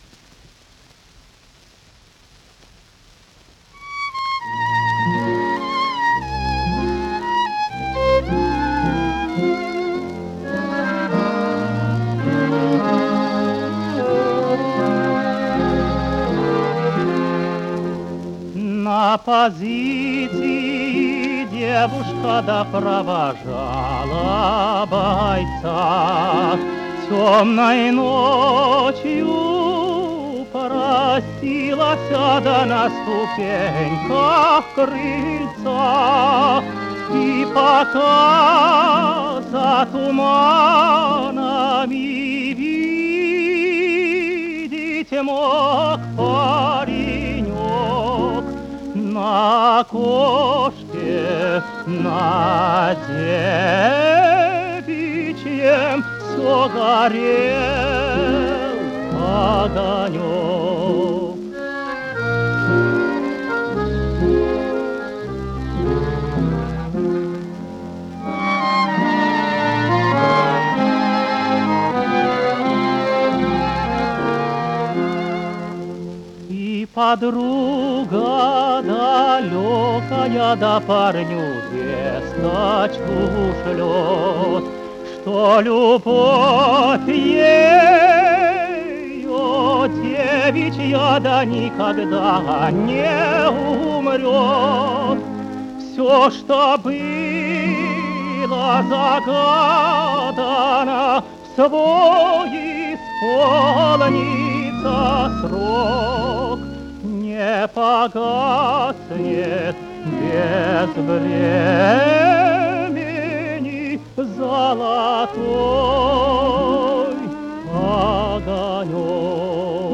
Каталожная категория: Тенор с оркестром |
Жанр: Песня
Вид аккомпанемента: Ансамбль
Место записи: Москва |
Скорость оцифровки: 78 об/мин |